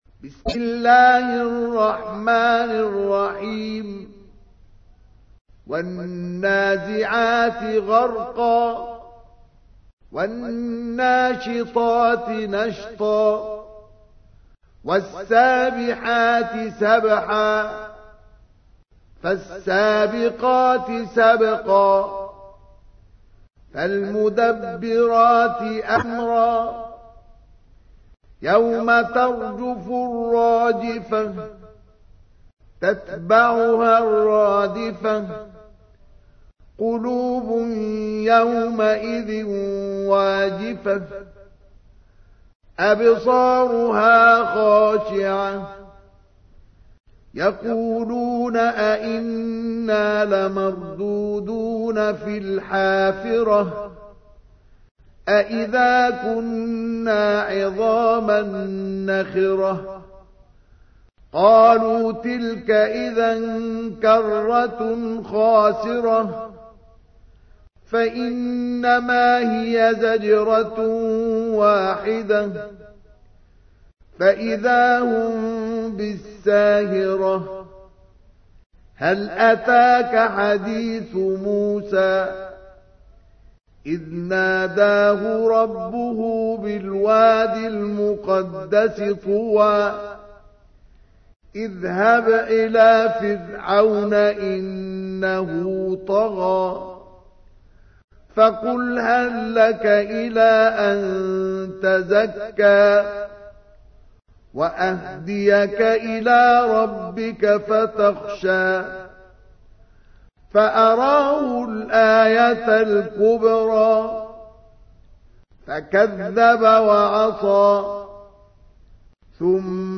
تحميل : 79. سورة النازعات / القارئ مصطفى اسماعيل / القرآن الكريم / موقع يا حسين